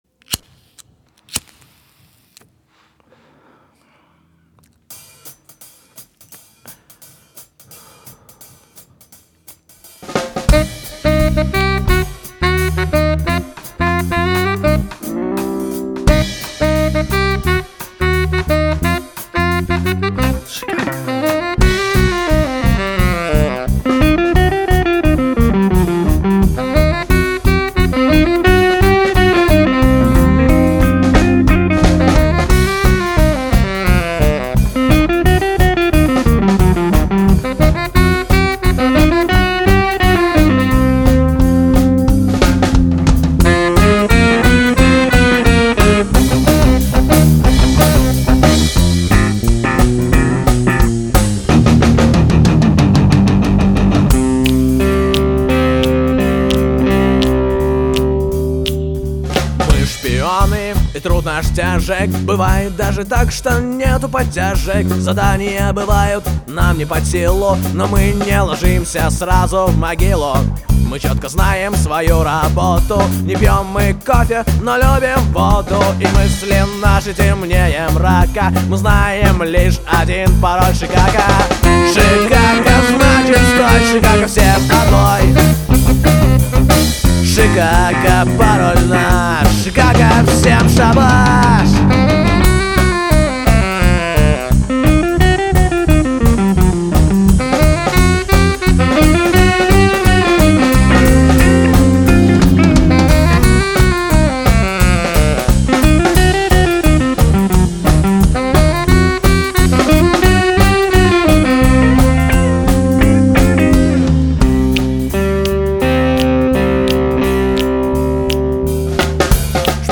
вокал
гитара
ударные
бас
саксофон